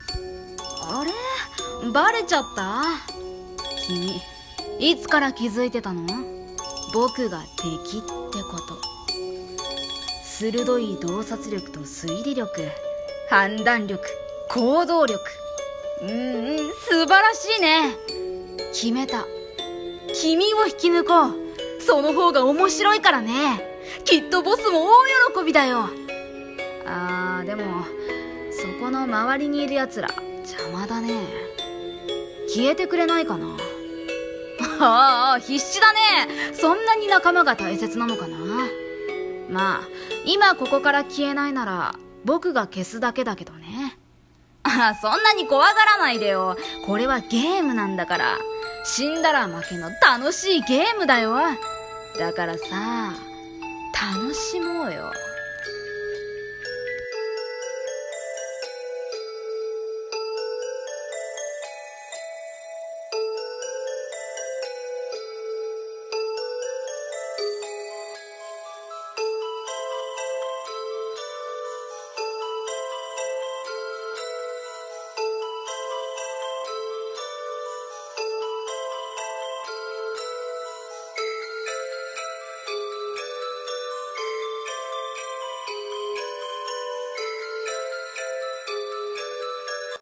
【1人声劇】たのしいゲーム